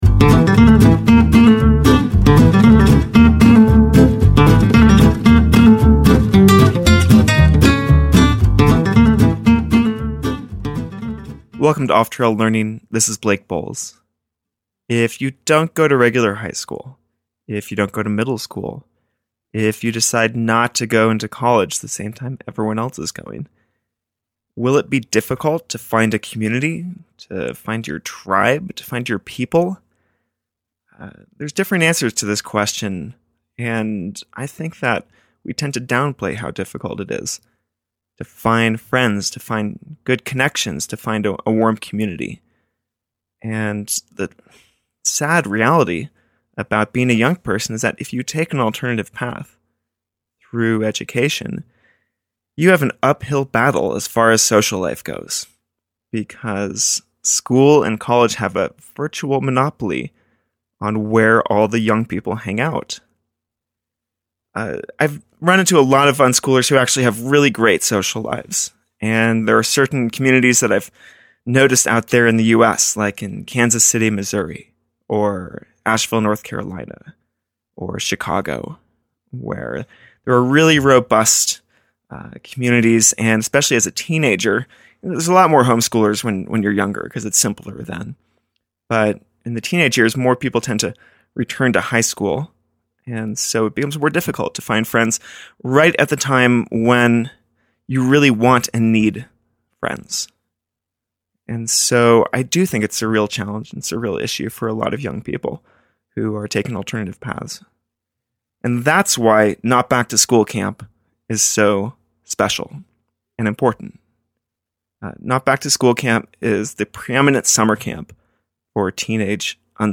We recorded the episode live at Not Back to School Camp 2016, Oregon Session 1.